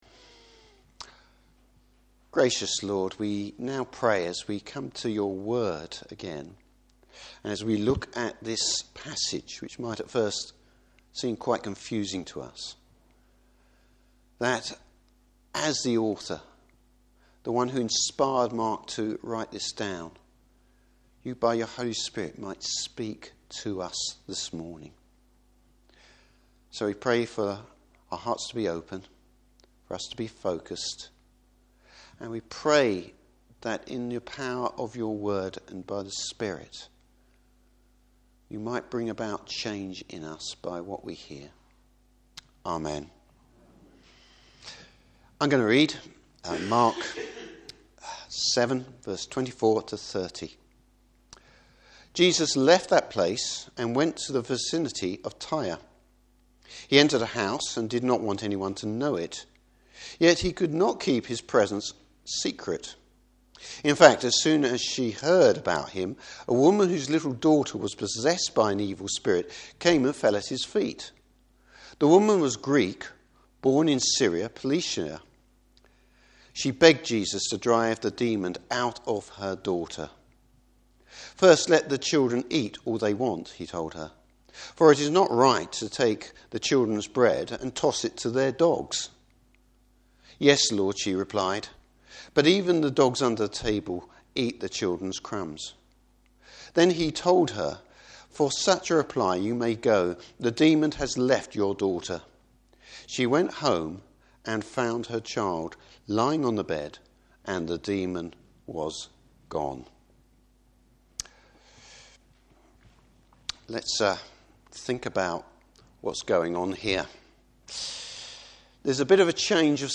Passage: Mark 7:24-30. Service Type: Morning Service The infinite nature God’s grace!